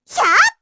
Lilia-Vox_Attack2_kr.wav